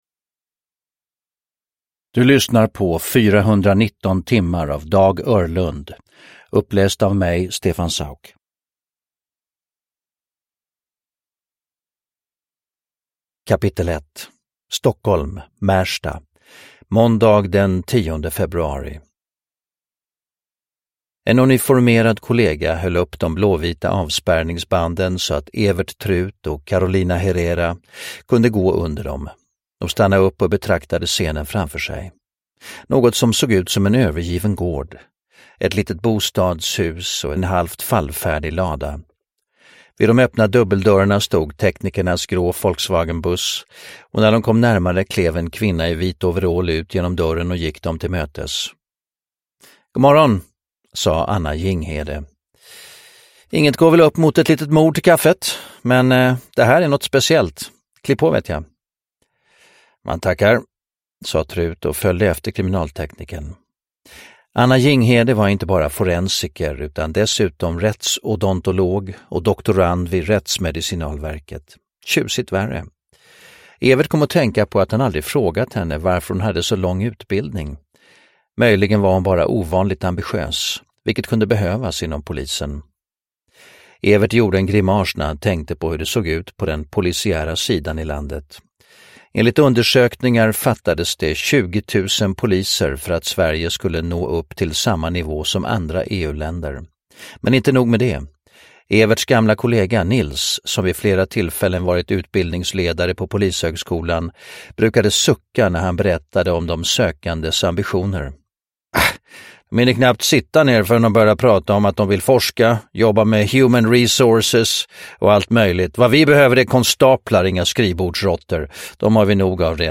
419 timmar (ljudbok) av Dag Öhrlund